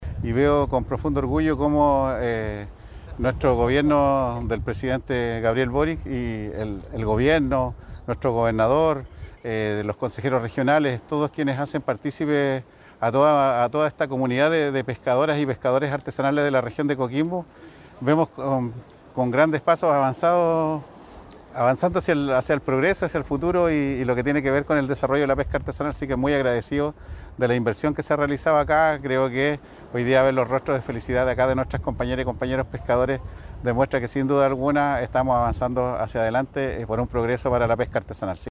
Por su parte, el alcalde de la comuna de Canela, Waldo Contreras, destacó que,
ALCALDE-COMUNA-CANELA-WALDO-CONTRERAS.mp3